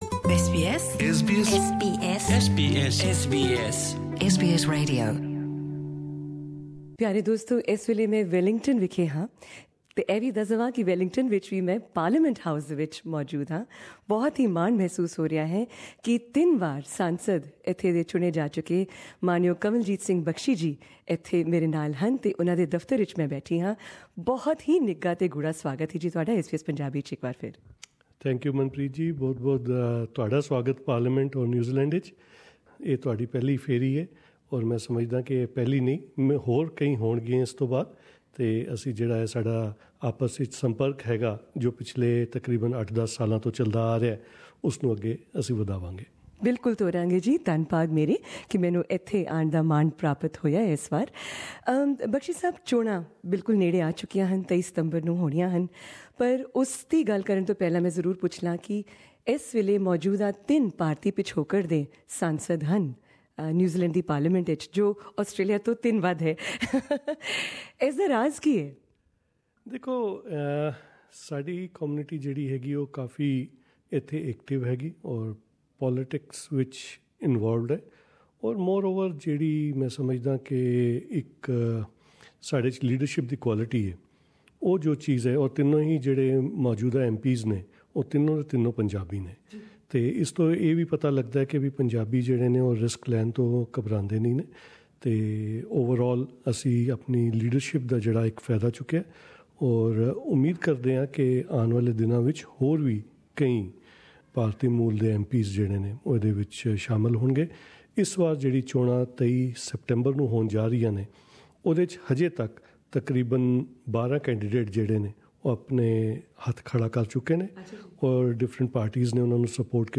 Three time MP in NZ parliament Kanwaljit Bakshi, in conversation
at NZ Parliament House, during her recent visit to Wellington.